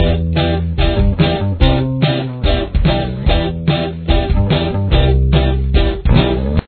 Main Riff
Guitar 1 Guitar 2